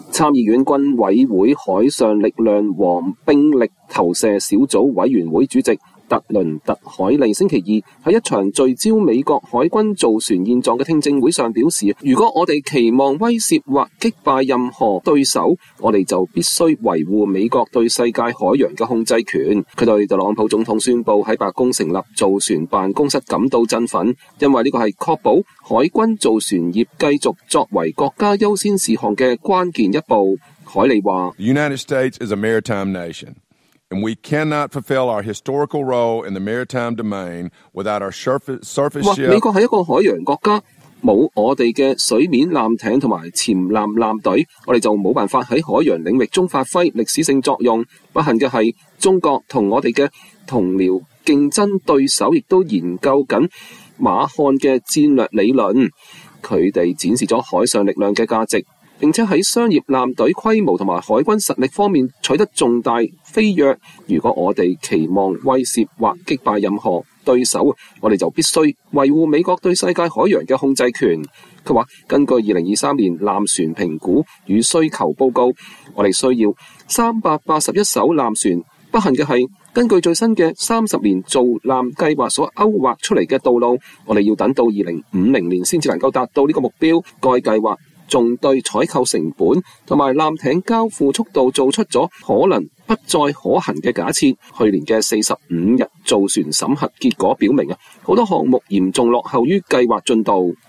美國國會眾議院軍委會海上力量和兵力投射小組委員會主席特倫特·凱利(Trent Kelly)2025年3月11日在委員會聽證會上致詞。